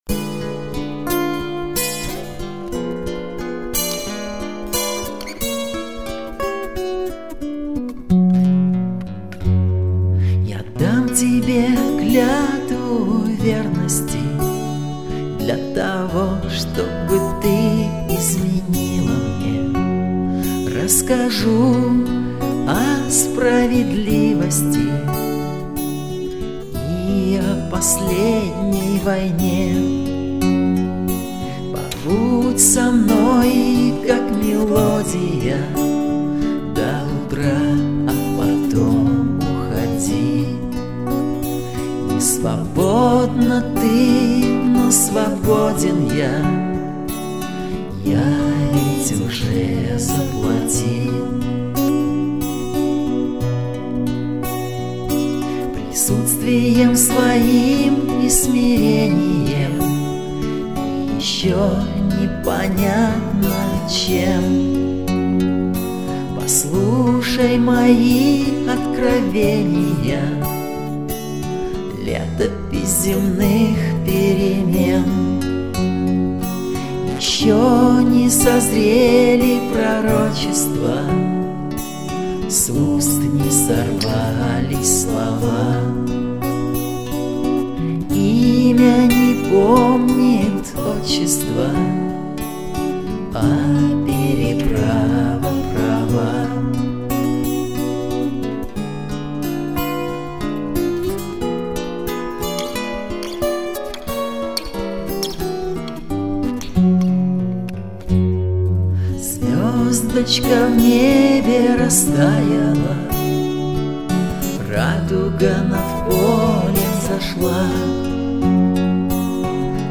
Есть в альбоме и Рок`н`Ролл
вокал, бэк вокал, акустические гитары